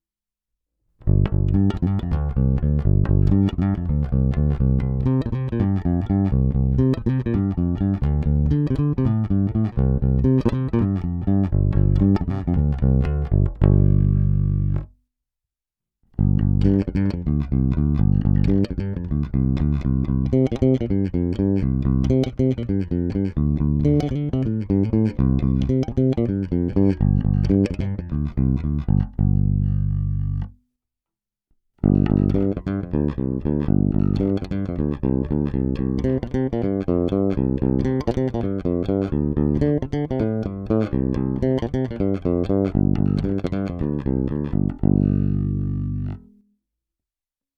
Není-li uvedeno jinak, nahrávky jsou provedeny rovnou do zvukové karty, bez stažené tónové clony a bez použití korekcí.
Hráno nad použitým snímačem, v případě obou hráno mezi nimi.